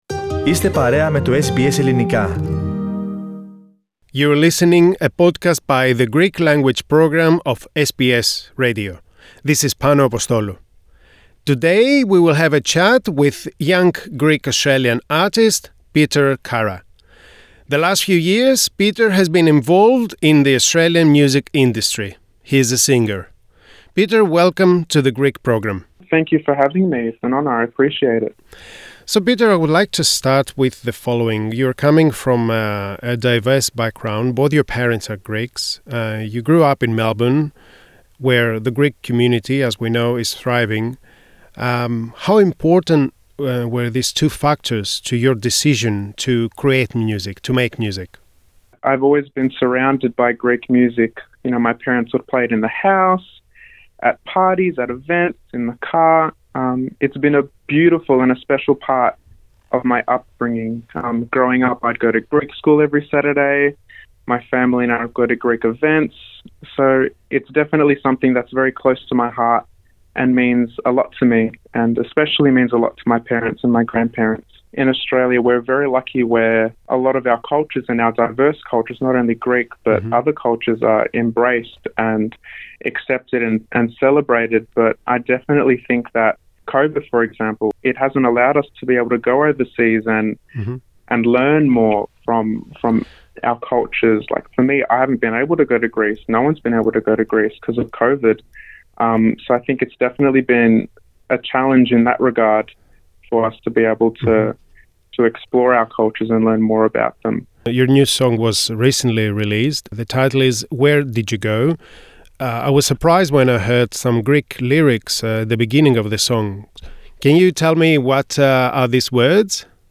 He talks to SBS Greek.